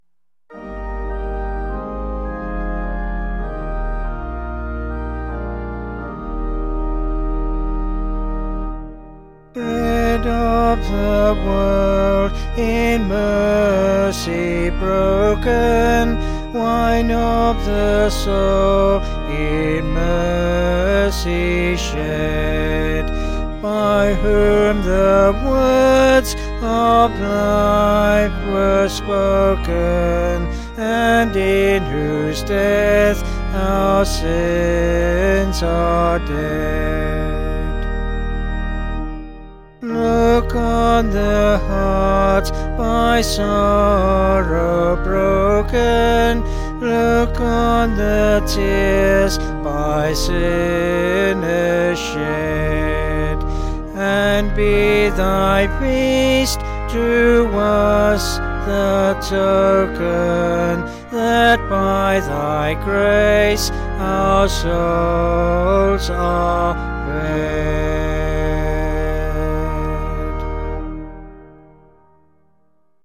(BH)   2/Eb
Vocals and Organ   704.2kb Sung Lyrics